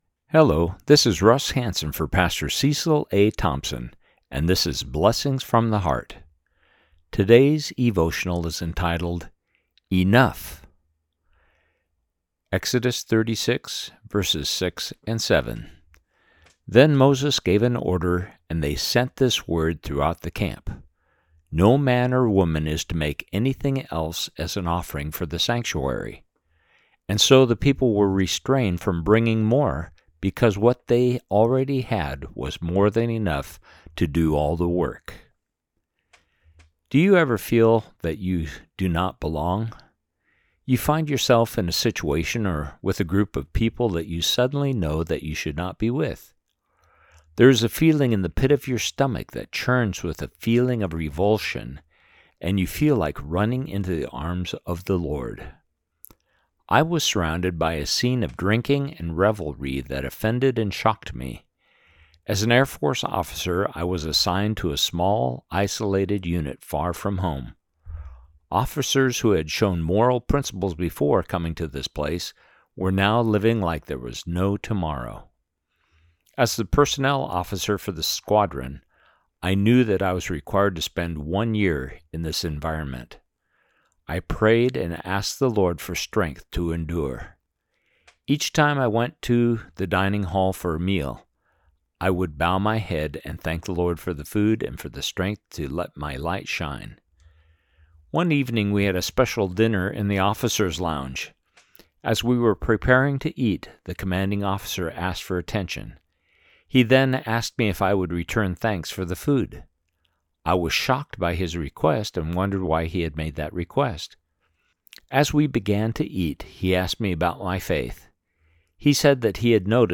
Exodus 36:6-7 – Devotional